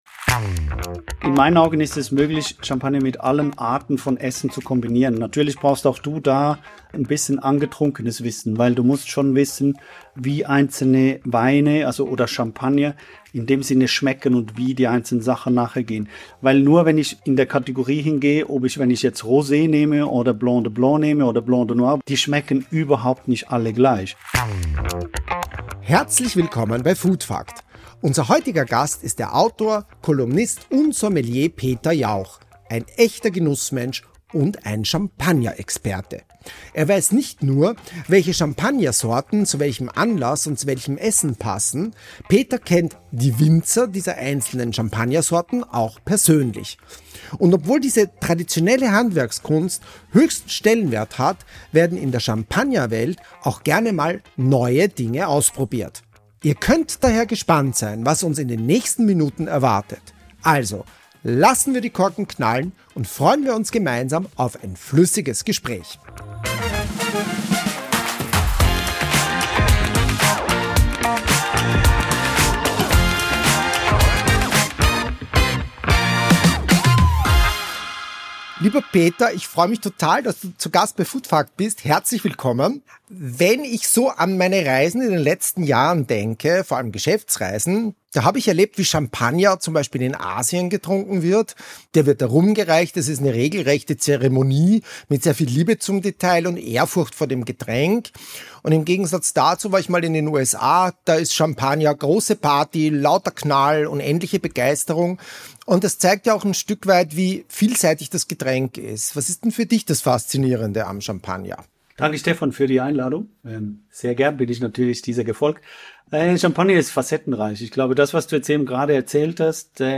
Freut euch auf ein spritziges Gespräch zum Jahresende!